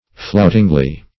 Search Result for " floutingly" : The Collaborative International Dictionary of English v.0.48: Floutingly \Flout"ing*ly\, adv. With flouting; insultingly; as, to treat a lover floutingly.